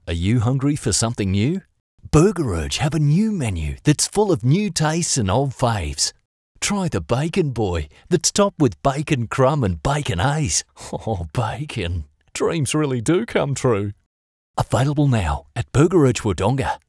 Award-winning Australian Voice. versatile, clear, and seriously experienced.
• Retail Friendly
• Professional Voice booth – acoustically treated.